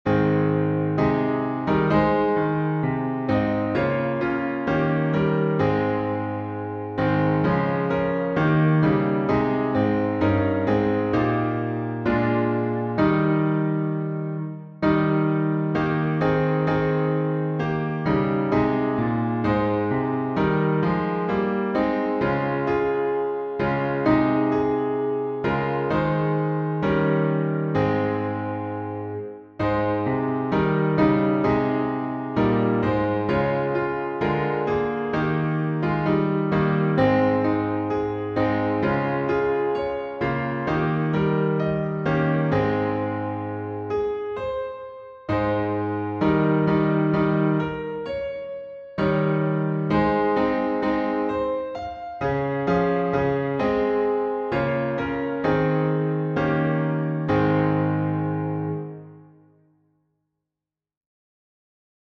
And Can It Be That I Should Gain — A flat, unified refrain.
Key signature: A flat major (4 flats) Time signature: 4/4